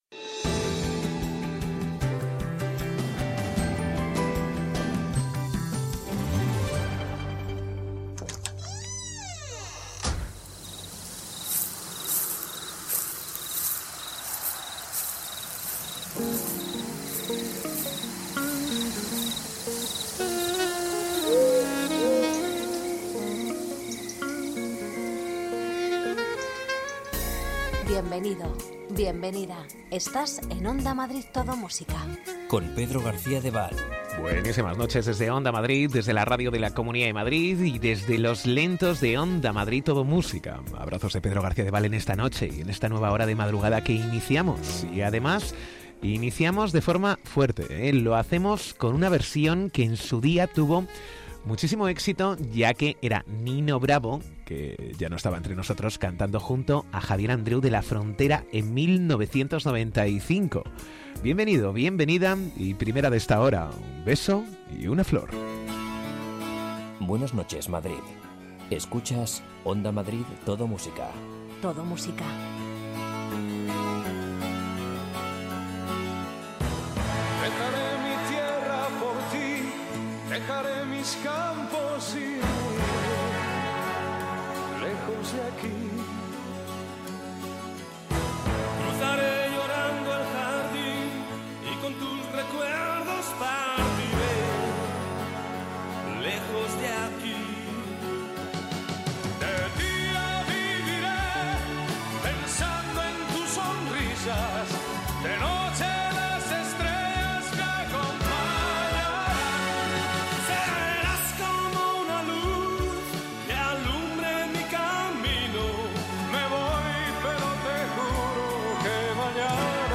Los mejores lentos
Ritmo tranquilo, sosegado, sin prisas...